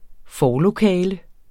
forlokale substantiv, intetkøn Bøjning -t, -r, -rne Udtale [ ˈfɒː- ] Betydninger rum som man skal igennem for at komme ind i et ofte større og mere betydningsfuldt rum Synonym forrum Vi træder ind i en slags forlokale.